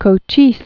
(kō-chēs, -chēz) Died 1874.